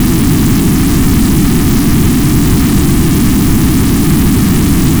spaceEngineLarge_002.ogg